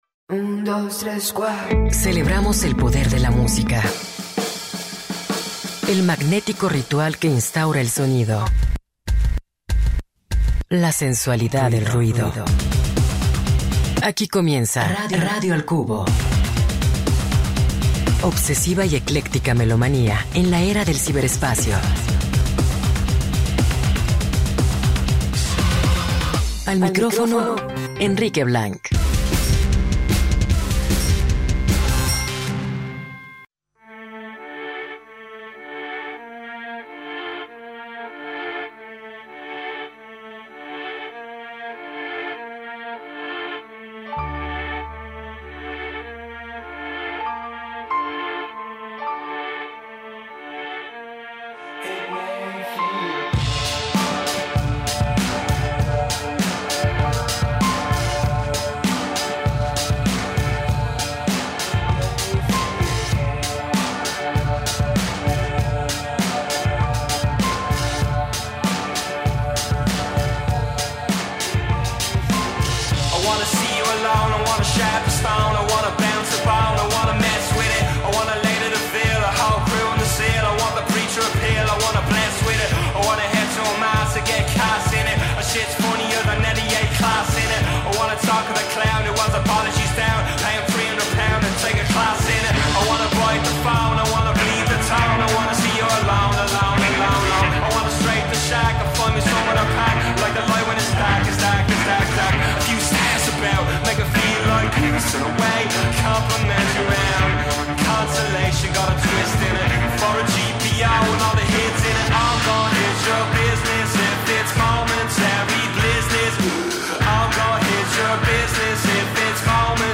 playlist de hallazgos musicales